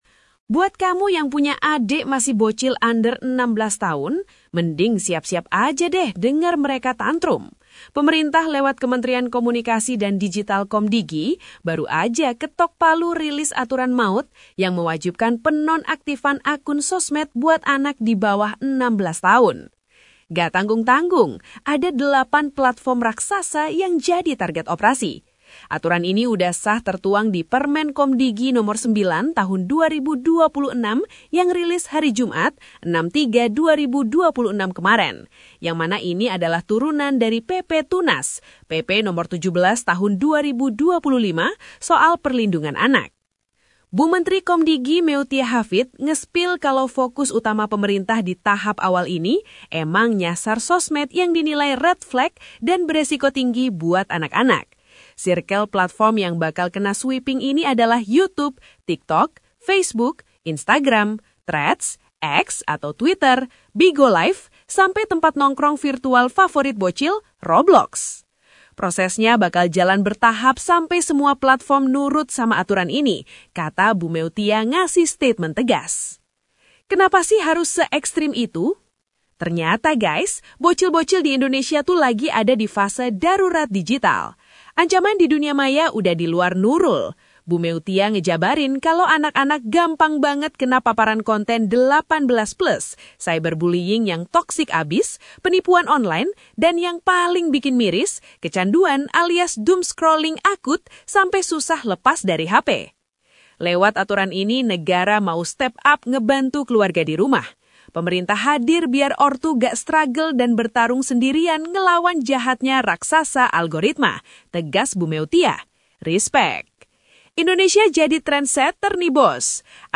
Redaksi | 08 Maret 2026 DENGARKAN BERITA INI Narator Digital DPD Golkar Kota Malang Buat kamu yang punya adek masih bocil under 16 tahun, mending siap-siap aja deh denger mereka tantrum.